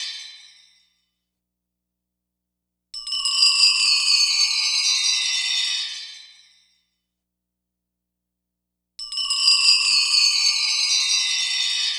Mid Chime.wav